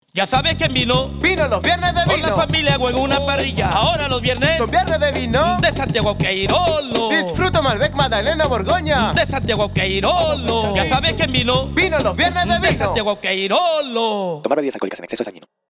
Spot-radio-15s-SQ1.mp3